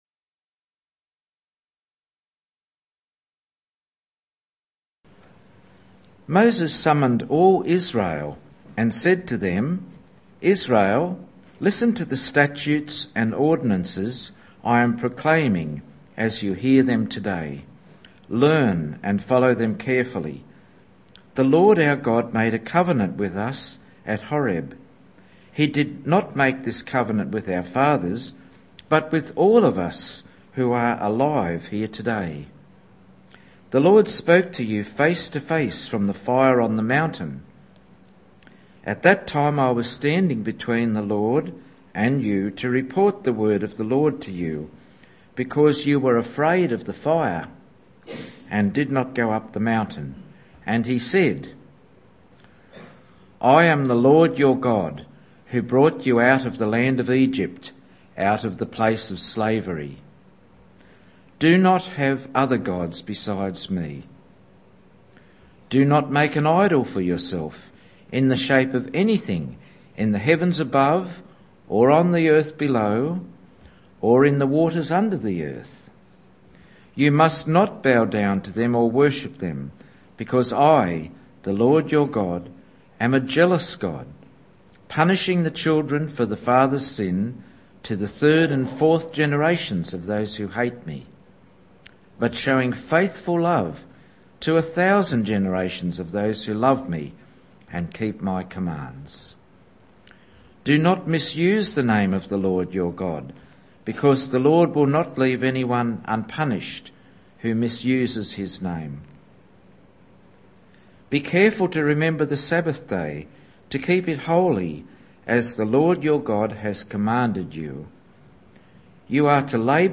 Evening Church